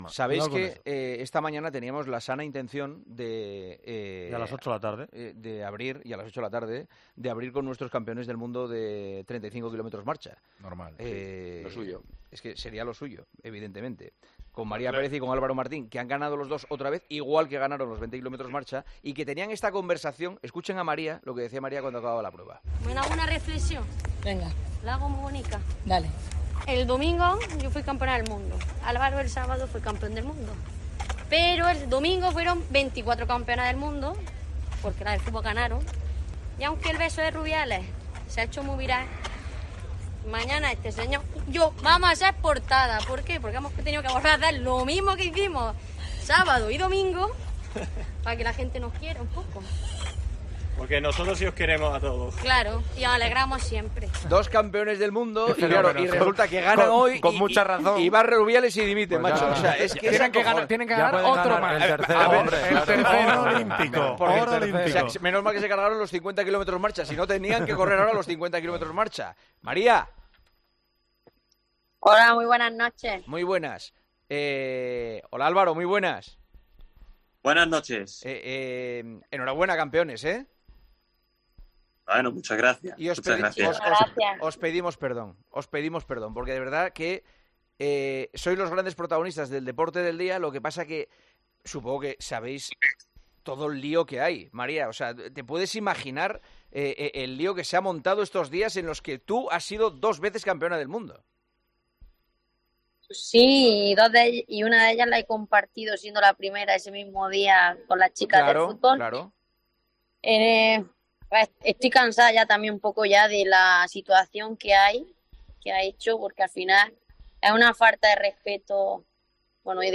AUDIO: Hablamos con Álvaro Martín y María Pérez, que esta mañana fueron lograron sus segundas medallas de oro en el Mundial, esta vez en la prueba de...